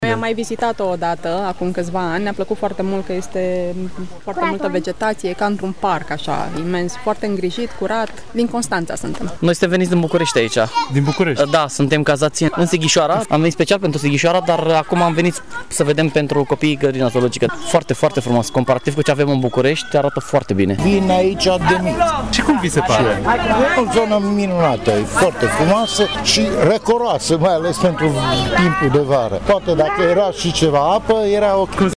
Vizitatorii grădinii zoologice provin din toate colțurile țării, iar unii vin la Târgu Mureș de zeci de ani: